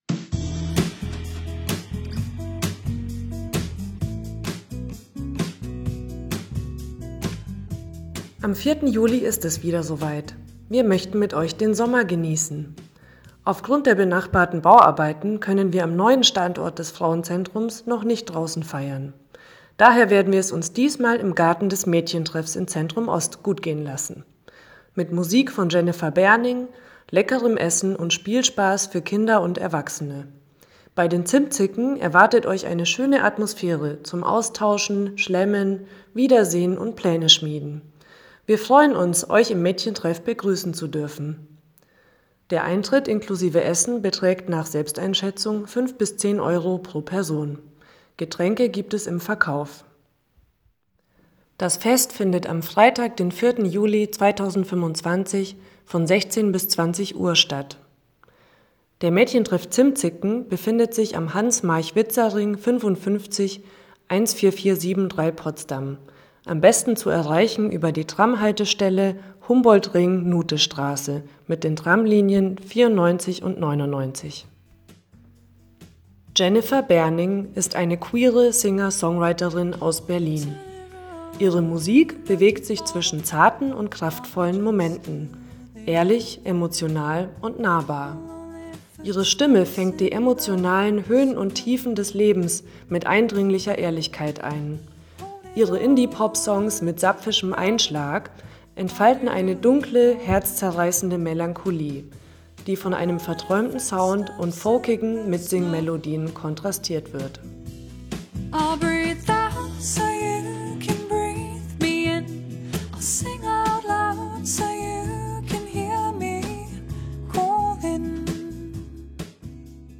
Audioflyer